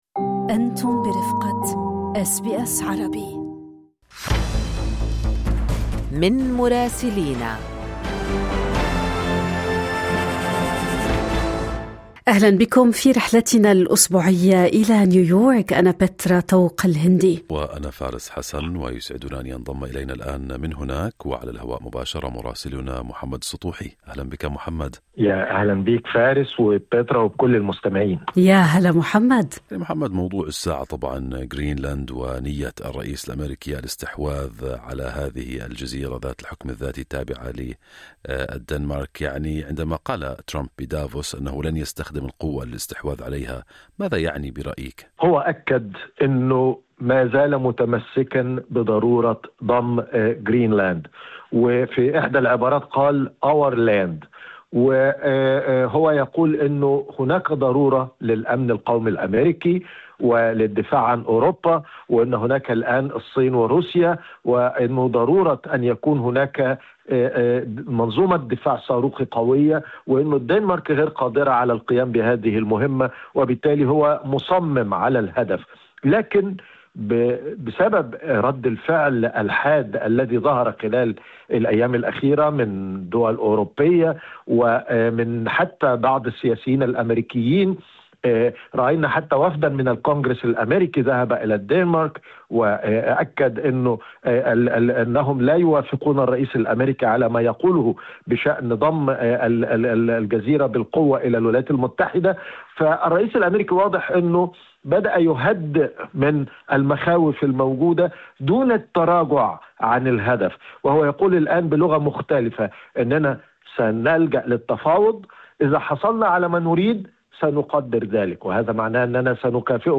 من مراسلينا: عين ترامب على غرينلاند.. مجلس السلام وأصداؤه وأخبار أخرى من نيويورك